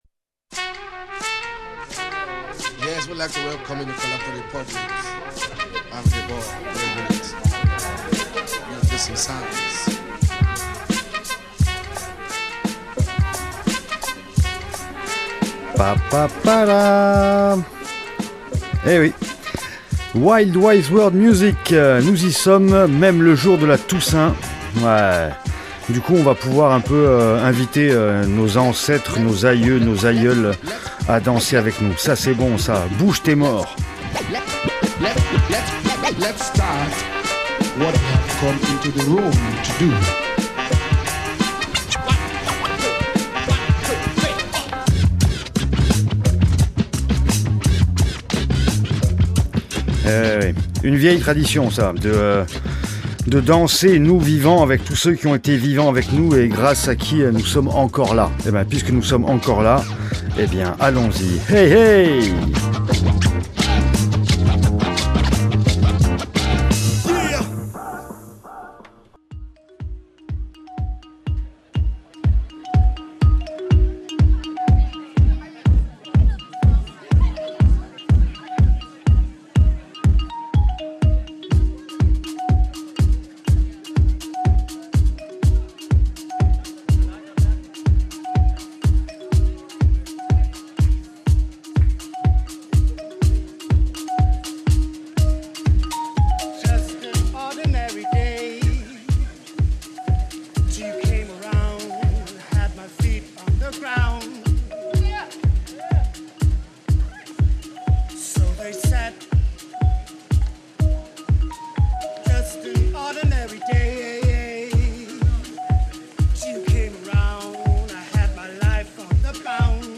remix tribal bass
Drums n Bass remix